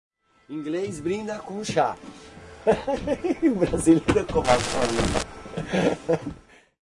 在巴西Guaruja的PraiaBranca录制。
Tag: 现场录音 讲话 谈话 语音